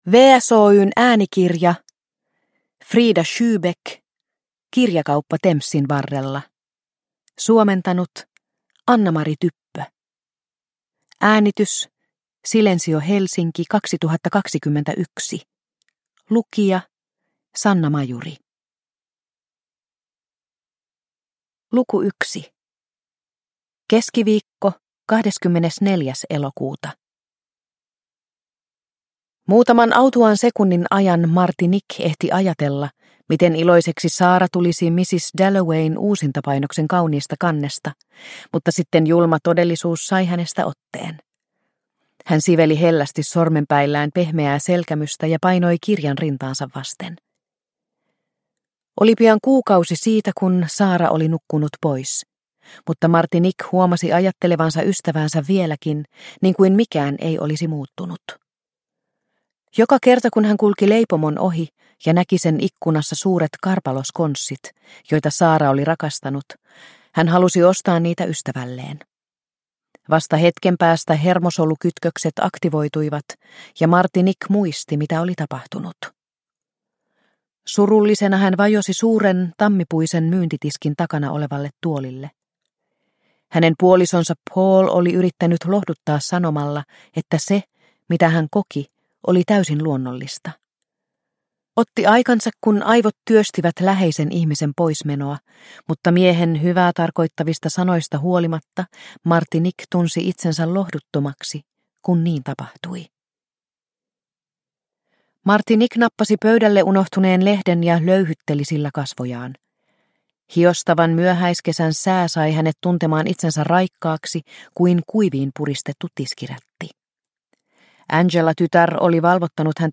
Kirjakauppa Thamesin varrella – Ljudbok – Laddas ner